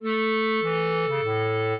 clarinet
minuet7-4.wav